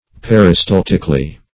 Per`i*stal"tic*al*ly, adv.